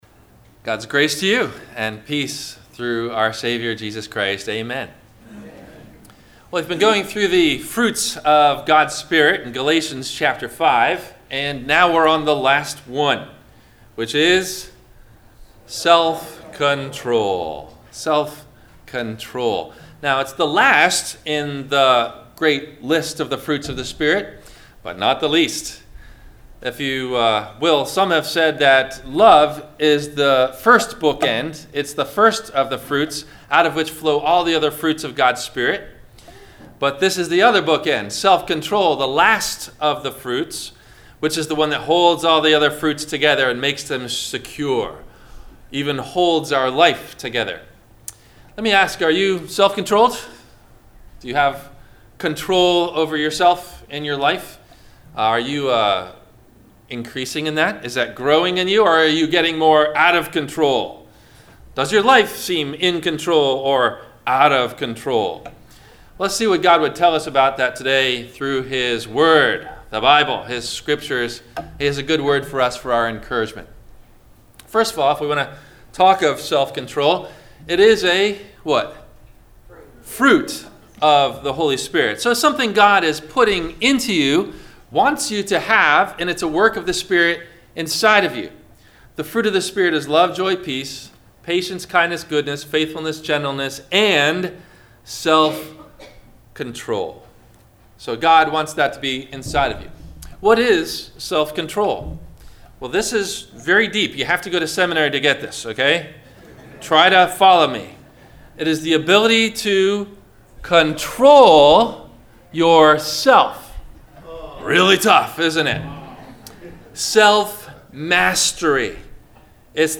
- Sermon - November 24 2019 - Christ Lutheran Cape Canaveral